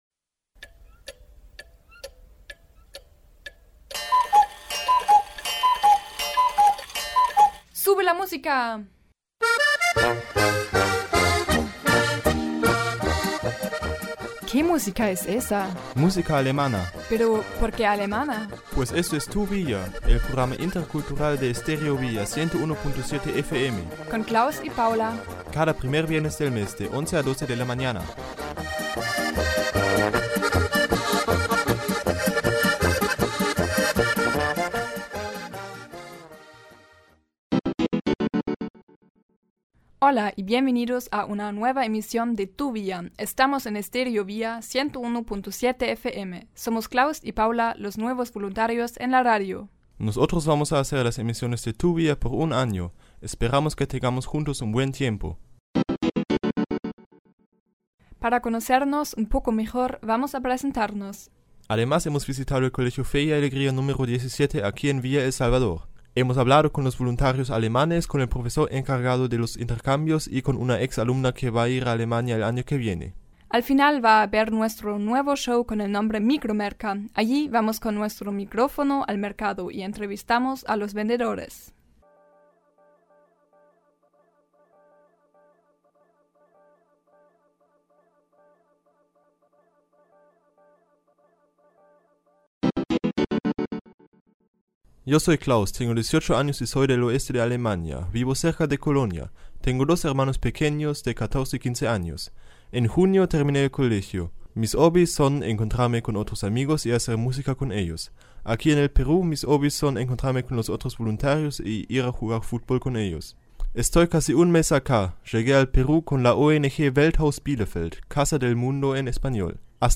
Das Thema war der deutsch-peruanische Freiwilligendienst. Daher wurden aktuelle deutsche Freiwillige und ihr Verantwortlicher interviewt. Außerdem hat eine angehende Süd-Nord-Freiwillige über ihren anstehenden Freiwilligendienst in Deutschland gesprochen.